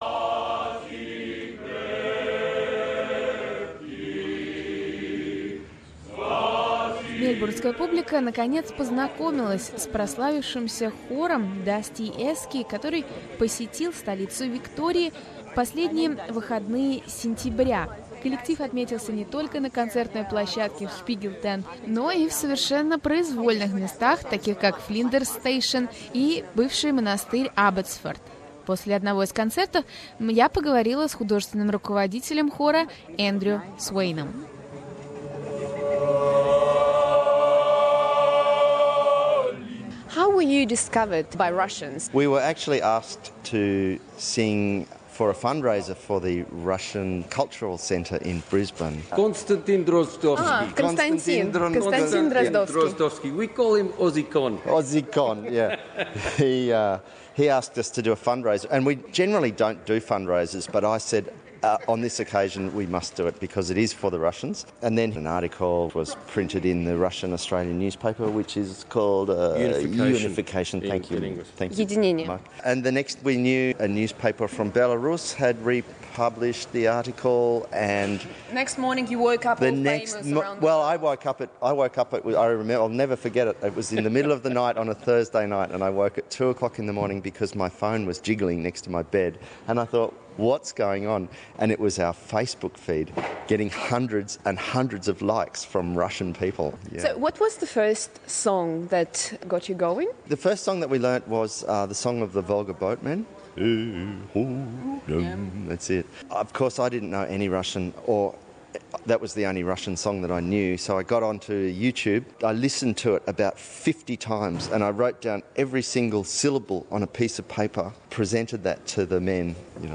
Male choir Dustyesky performed in Melbourne Spiegeltent brightening up the murky light of the venue with their singing. 28 Aussie men dressed up like 1930s Soviet proletarians build an instant connection with the audience be it of Russian or any other background.